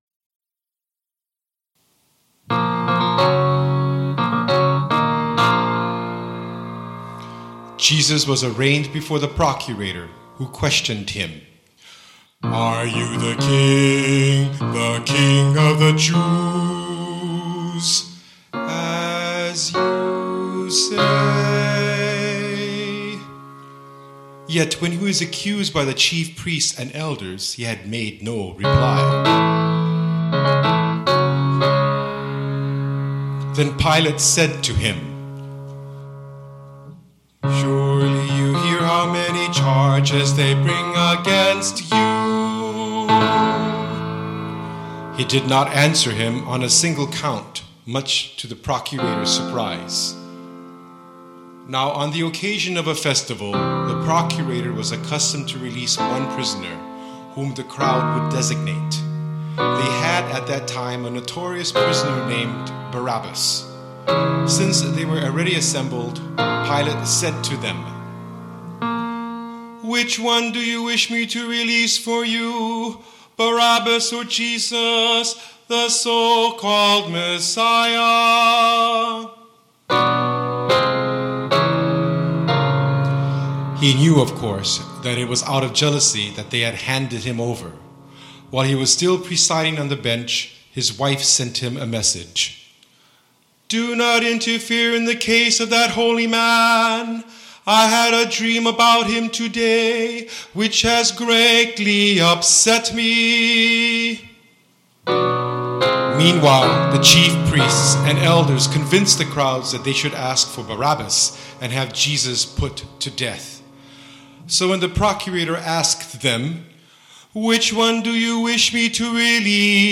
following mp3's are "practice" recordings